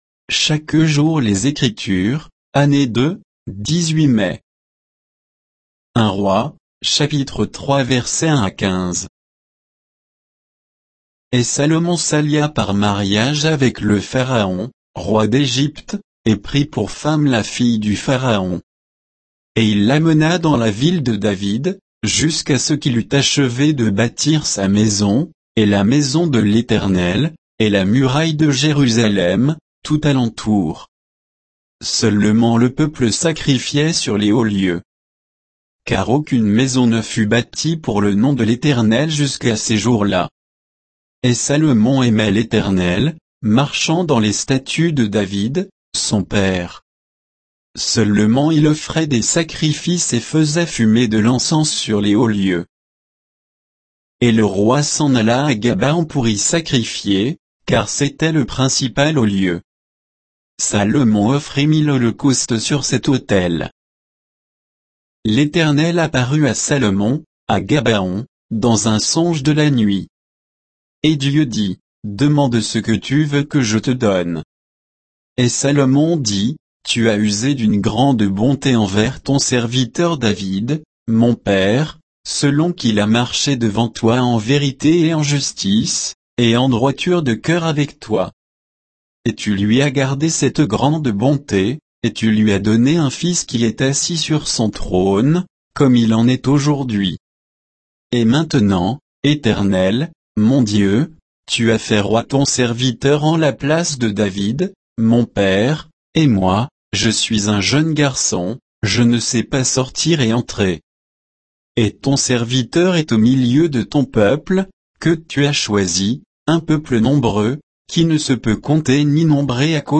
Méditation quoditienne de Chaque jour les Écritures sur 1 Rois 3